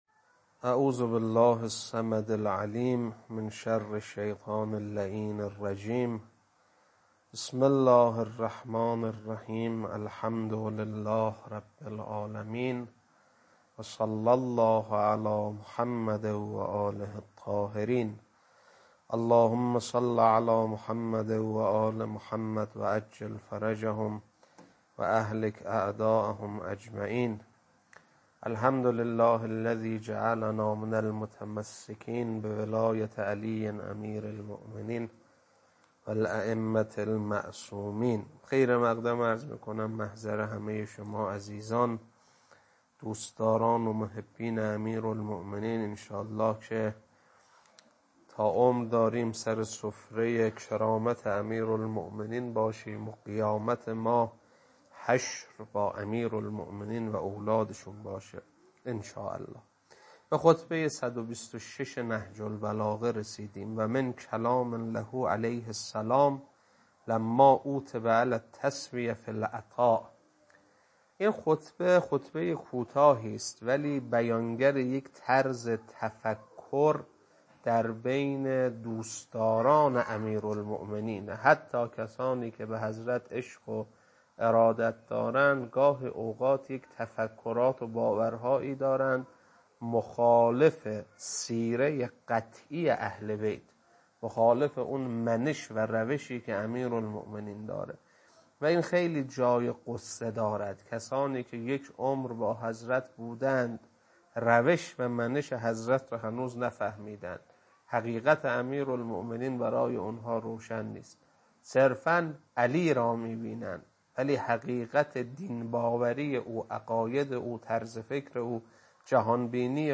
خطبه 126.mp3